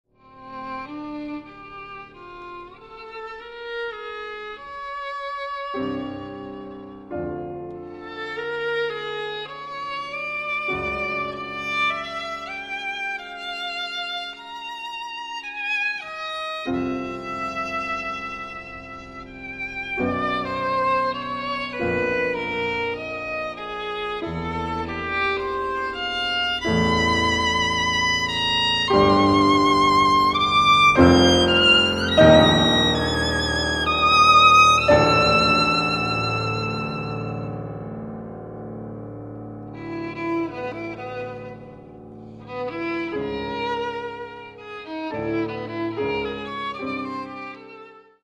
Каталог -> Классическая -> Нео, модерн, авангард